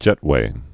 (jĕtwā)